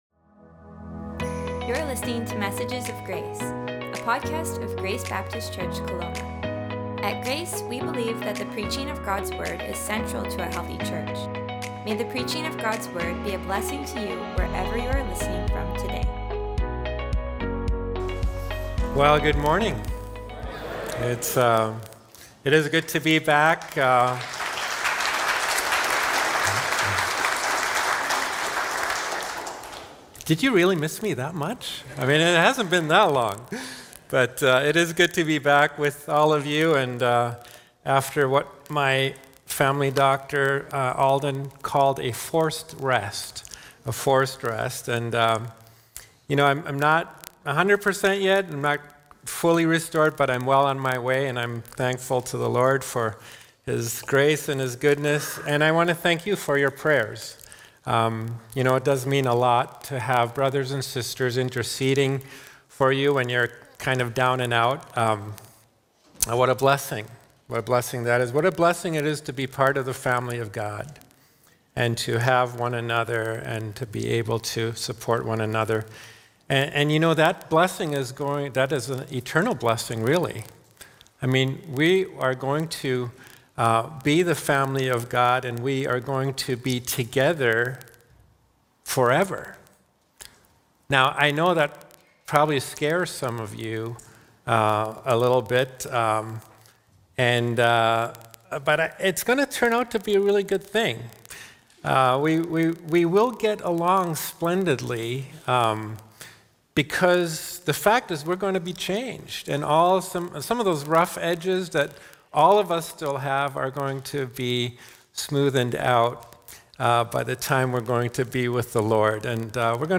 Sermons | Grace Baptist Church